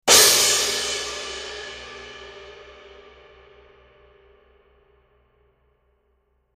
Sabian 17" HHX X-Treme Crash Cymbal
A highly effective blend of dark and junky thin crash explosion with the raw, biting attack of a Chinese cymbal.
• Style - Vintage
• Sound - Dark